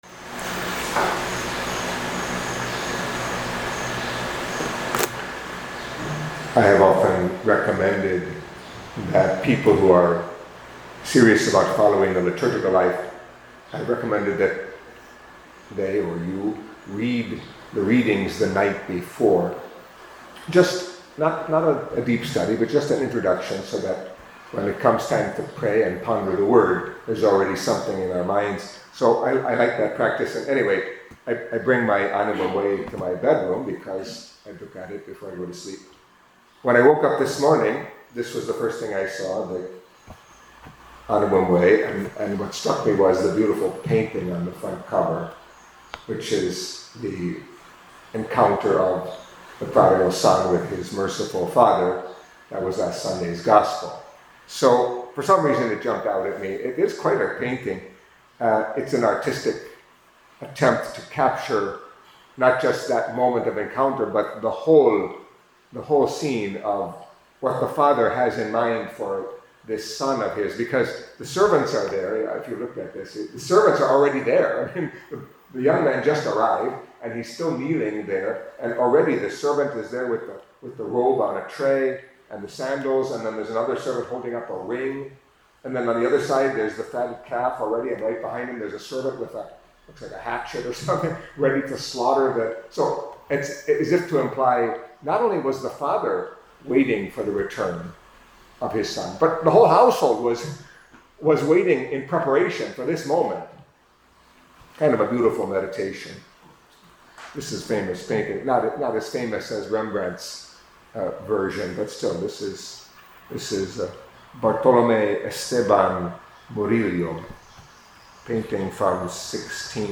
Catholic Mass homily for Thursday of the Fourth Week of Lent